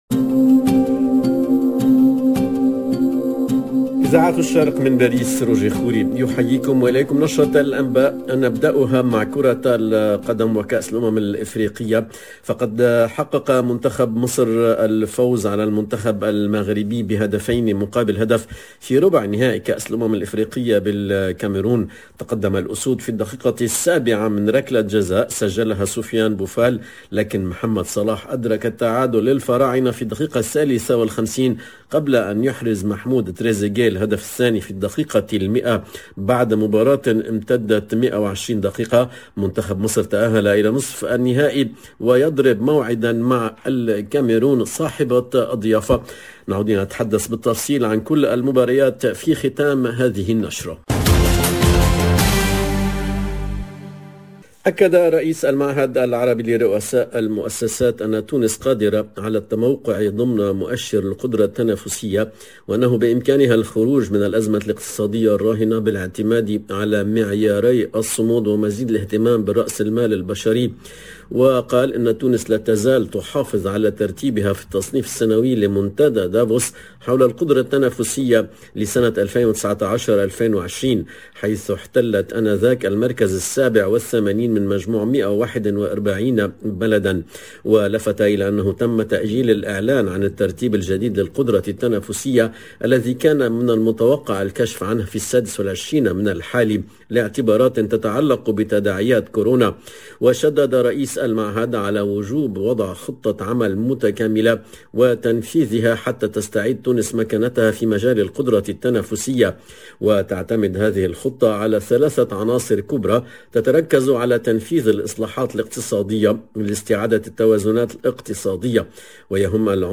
LE JOURNAL DE MIDI 30 EN LANGUE ARABE DU 31/01/22